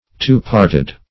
Search Result for " two-parted" : The Collaborative International Dictionary of English v.0.48: Two-parted \Two"-part`ed\ (-p[aum]rt`[e^]d), a. (Bot.)
two-parted.mp3